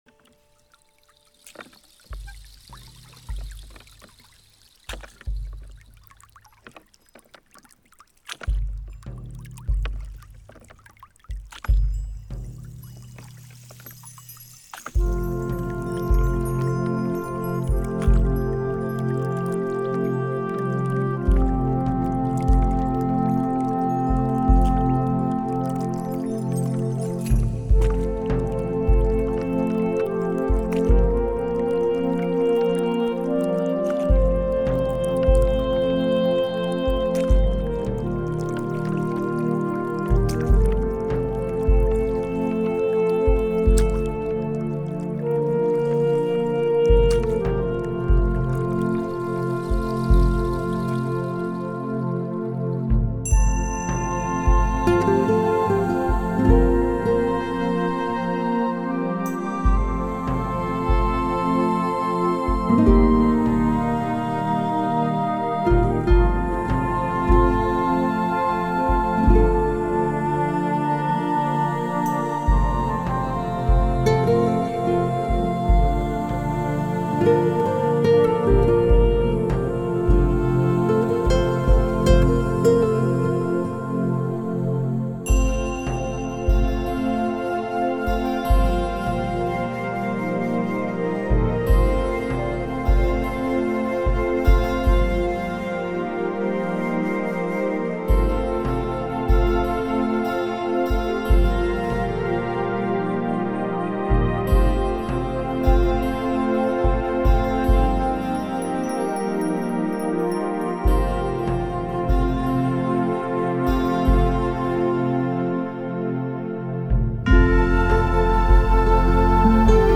Жанр: Relax